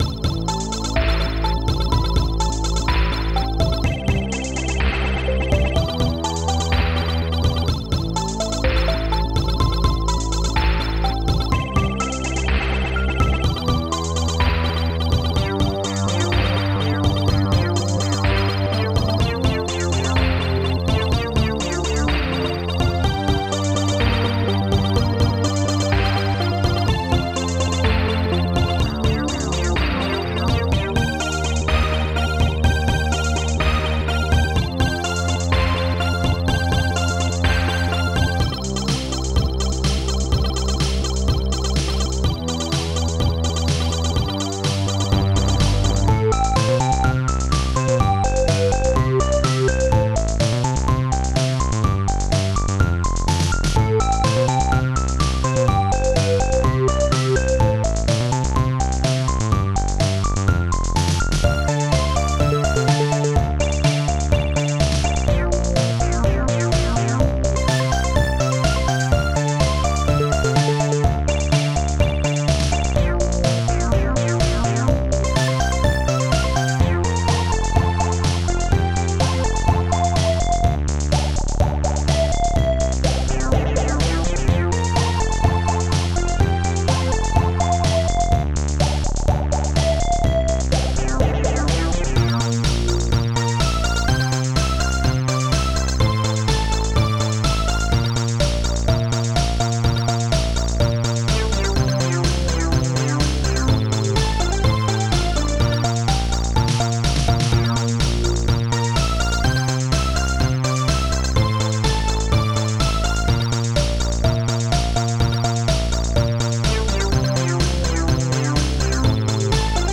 Protracker Module
Instruments pingbells analogstring dreambells korgbeau strings1 sinecz polysynth hihat2 popsnare2 bassdrum3